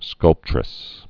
(skŭlptrĭs)